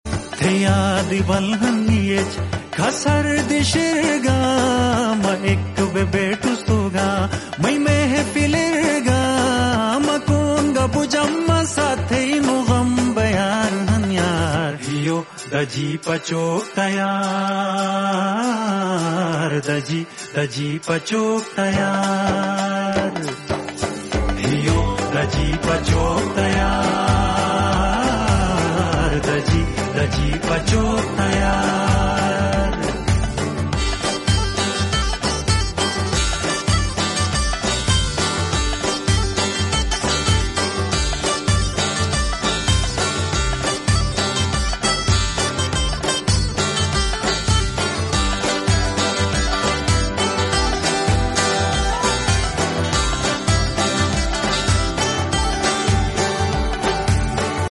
Shina & Khowar Mix Song